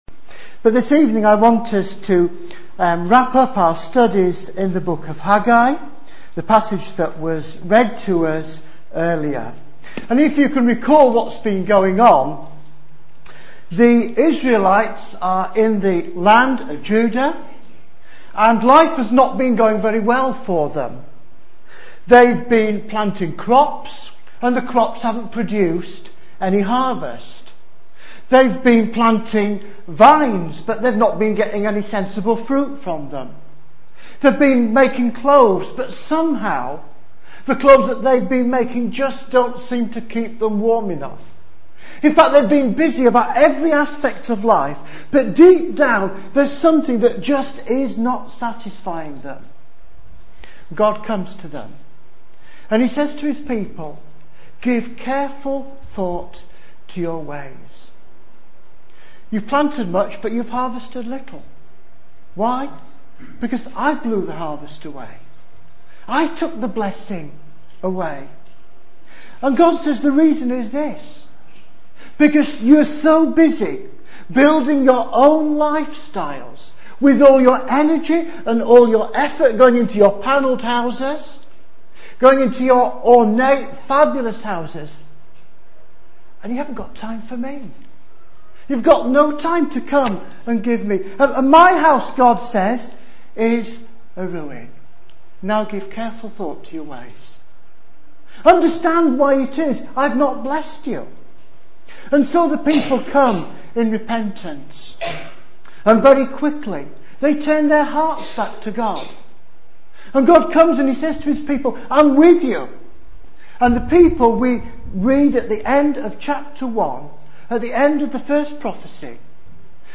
Latest Episode Haggai 2:10-23 You ain't seen nothing yet Download the latest episode Note: in some browsers you may have to wait for the whole file to download before autoplay will launch. A series of sermons on the prophet Haggai A short series of sermons preached on Sunday evening in Spring/Summer 2009 at Emmanuel Church